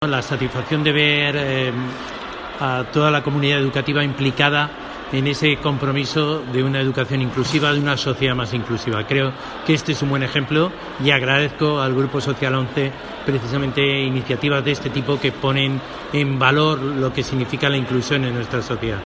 Con estas palabras resumía el consejero de Educación e Investigación de la Comunidad de Madrid, Rafael Van Grieken, el acto celebrado en el Centro de Recursos Educativos (CRE) de la ONCE en la capital este pasado 8 de mayo.